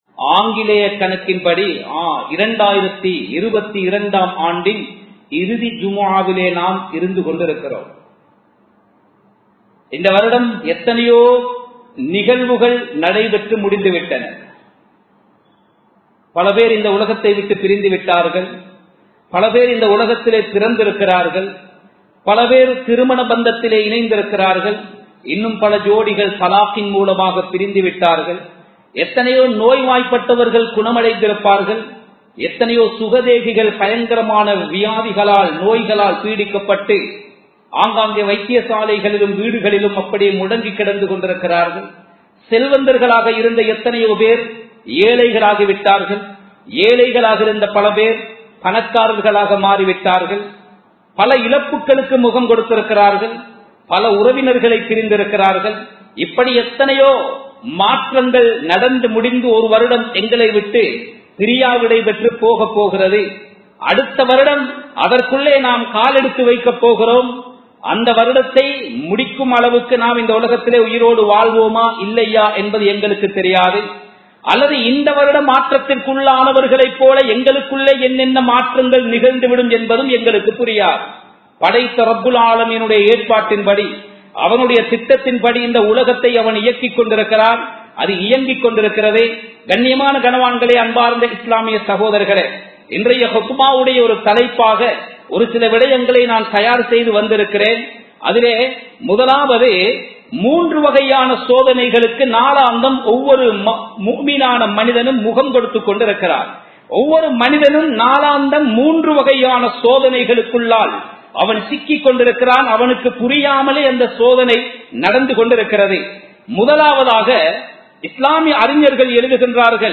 உழைப்பும் வாழ்க்கையும் | Audio Bayans | All Ceylon Muslim Youth Community | Addalaichenai
Colombo 11, Samman Kottu Jumua Masjith (Red Masjith)